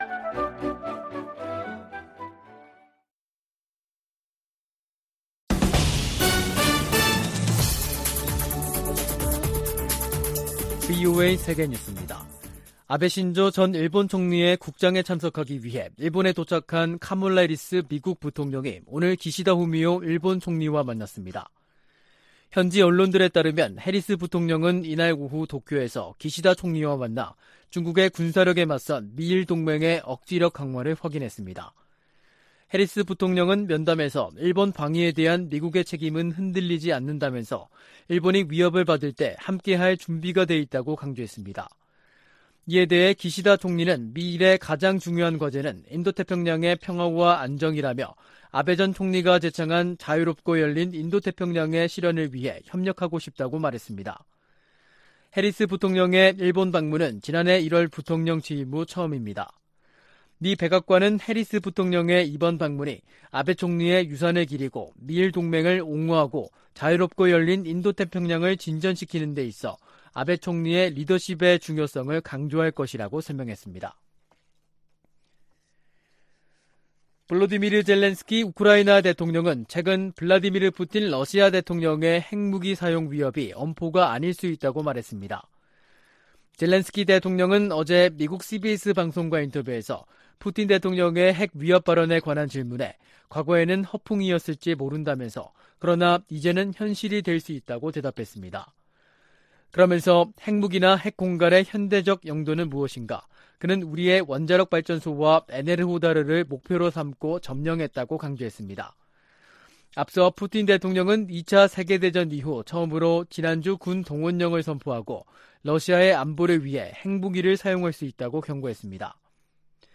VOA 한국어 간판 뉴스 프로그램 '뉴스 투데이', 2022년 9월 26일 2부 방송입니다. 한국 정부가 북한의 탄도미사일 도발을 규탄했습니다. 미 국무부도 북한 탄도미사일 발사를 비판하며 유엔 안보리 결의 위반임을 강조했습니다. 북한 신의주와 중국 단둥을 오가는 화물열차가 150일 만에 운행을 재개했습니다.